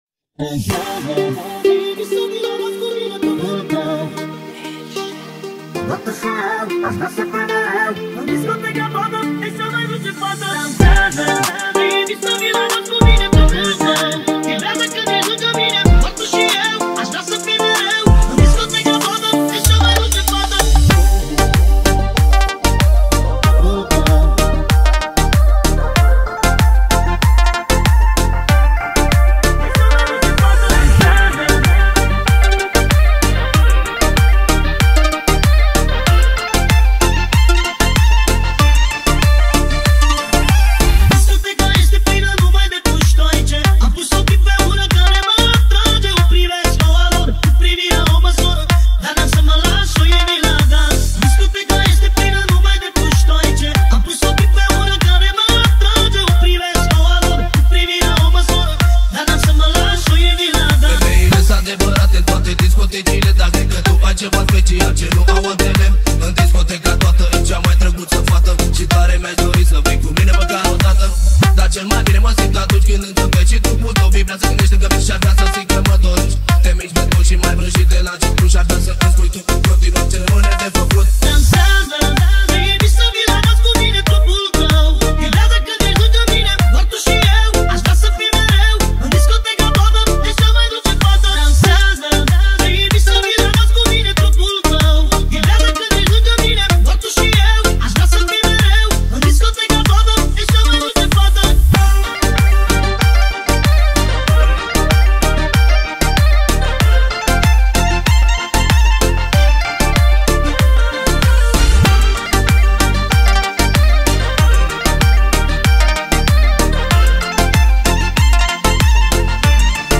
Скачать музыку / Музон / Восточные песни и музыка